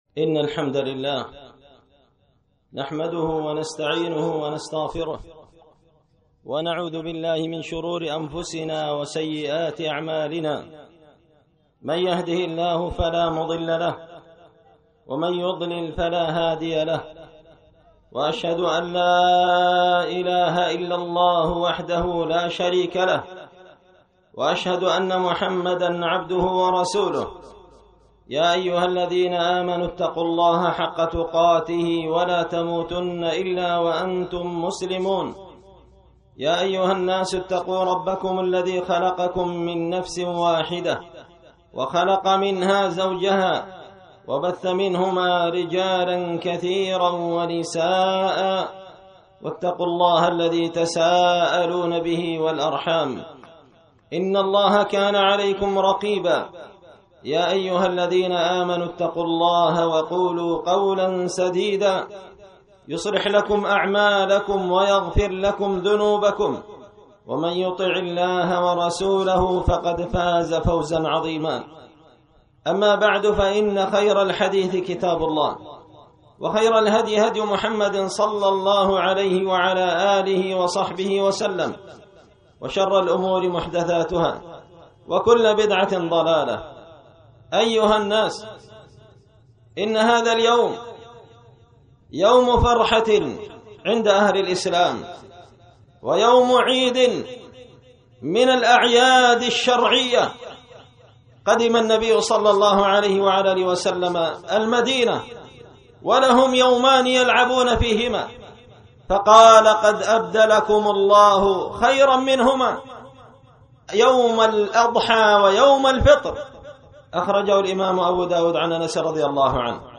خطبة عيد الفطر
خطبة_عيد_الفطر.mp3